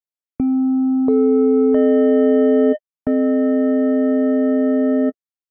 The 3:5:7 chord may thus be considered the major triad of the BP scale.